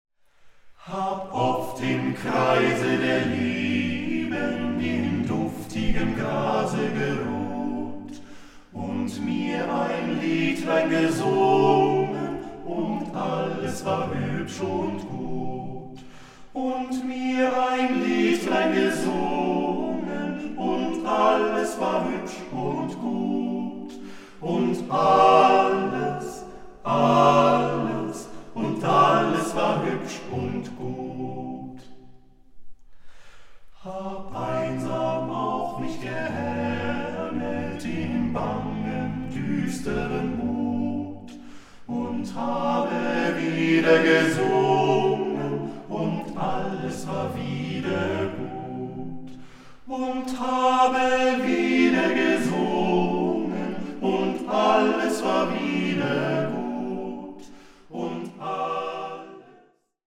award-winning vocal ensemble
the eight singers revive an entire folk culture!